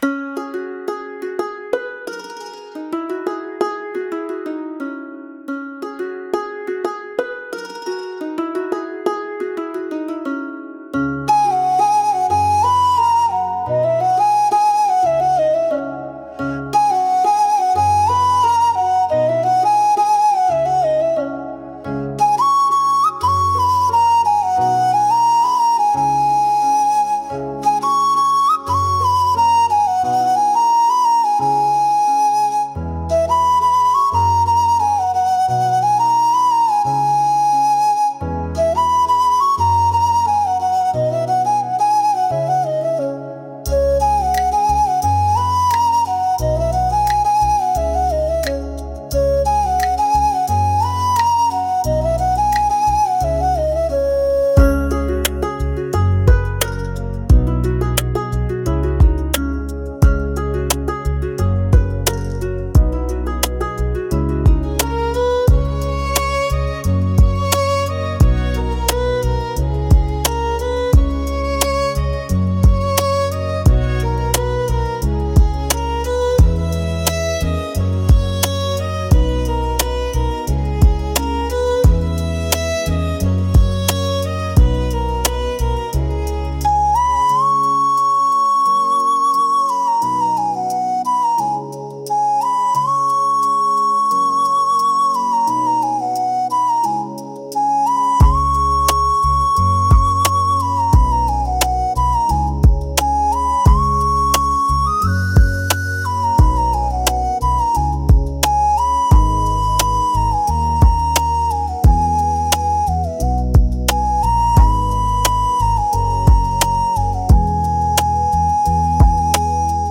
C# Minor – 88 BPM
Bollywood
Romantic